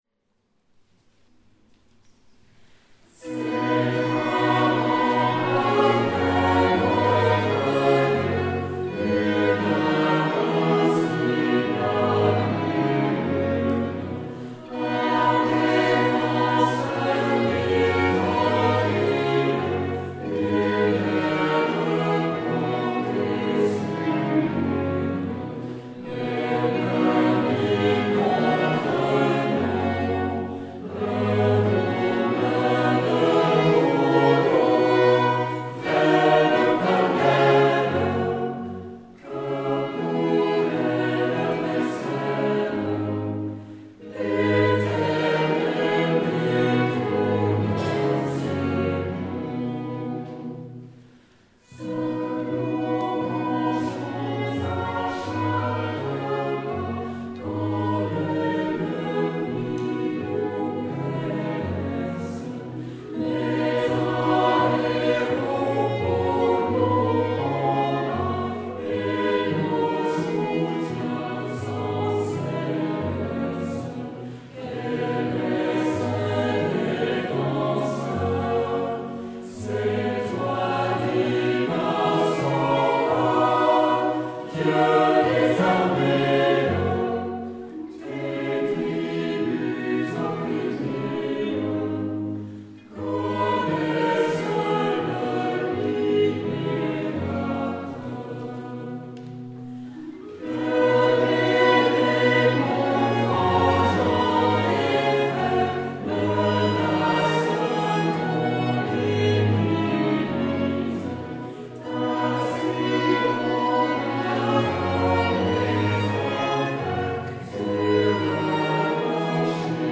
Ensemble Vocal Amaryllis et ensemble instrumental
Concerts donnés devant un total d'environ 300 auditeurs
C'est un rempart que notre Dieu, Hymne de Martin Luther, traduit et harmonisé par A.H.T. Lutteroth